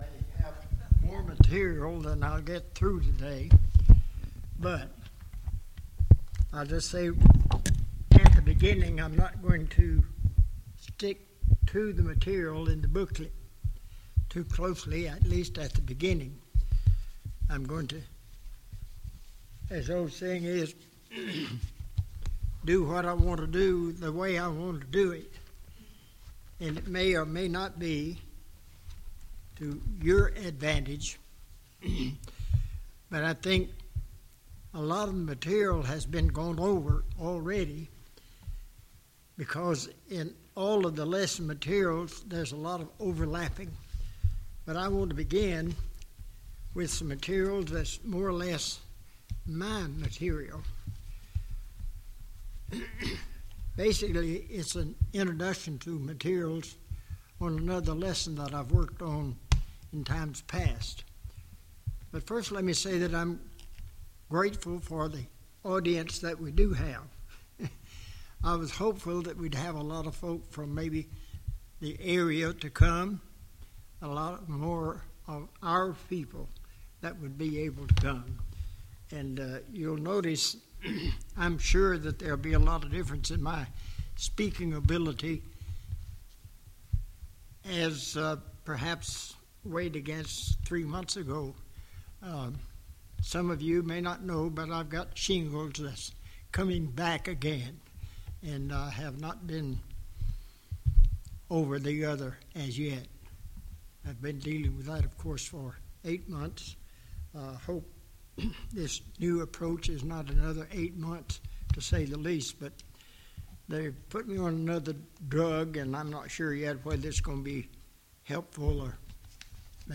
12 Service Type: VBS Adult Class « Lesson 1